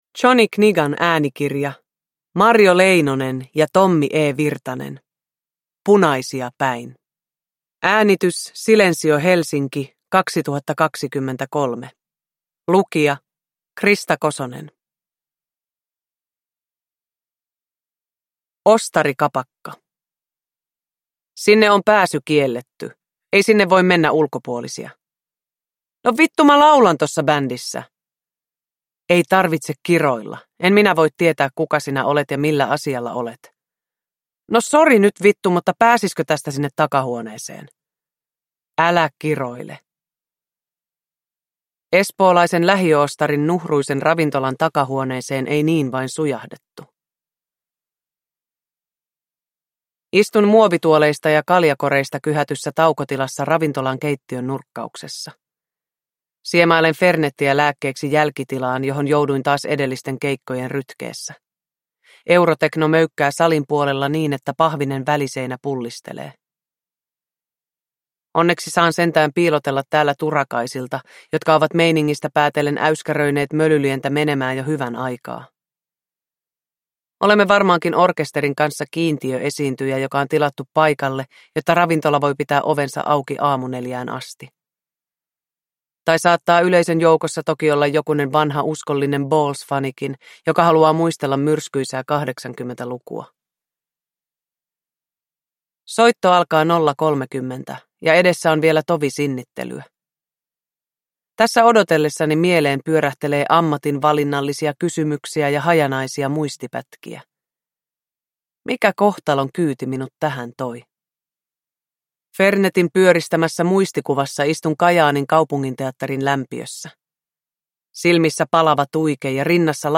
Uppläsare: Krista Kosonen